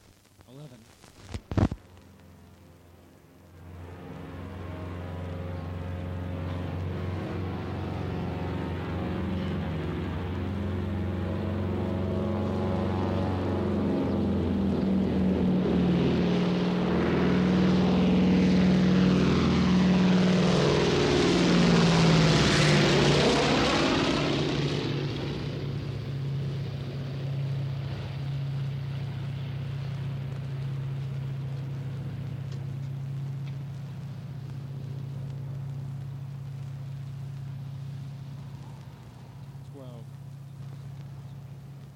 复古双引擎螺旋桨飞机 " G0622 C47双引擎螺旋桨飞机
描述：C47两架发动机螺旋桨飞机没有撞击，然后是出租车。 这些是20世纪30年代和20世纪30年代原始硝酸盐光学好莱坞声音效果的高质量副本。
我已将它们数字化以便保存，但它们尚未恢复并且有一些噪音。
标签： 螺旋桨 光学 飞机 两架 复古 发动机
声道立体声